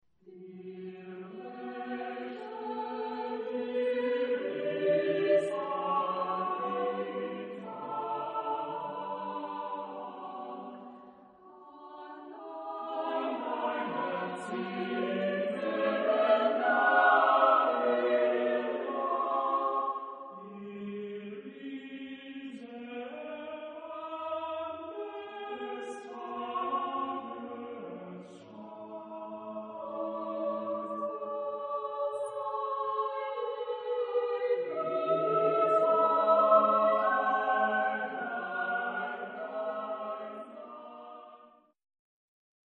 Genre-Style-Forme : Cycle ; Pièce chorale ; Profane
Type de choeur : SATB  (4 voix mixtes )
Tonalité : libre